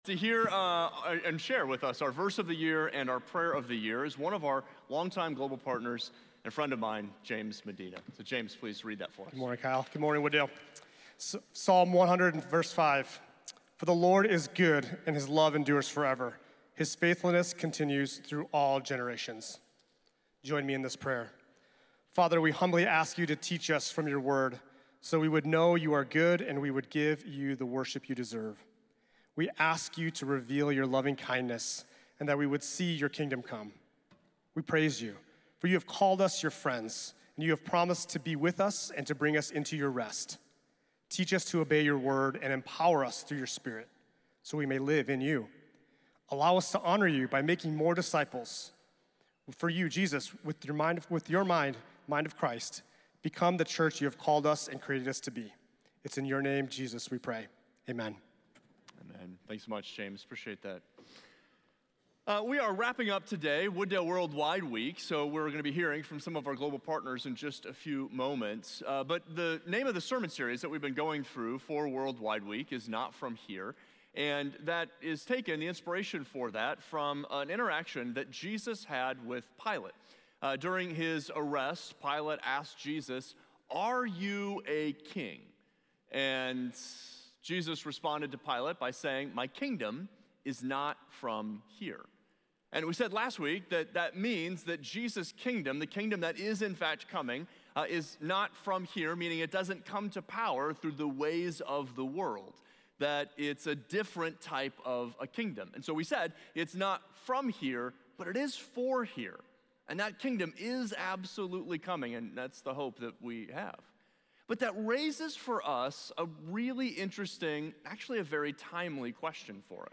Share this Sermon Facebook Twitter Pinterest Previous The God Who Restores Next Friends With God View Series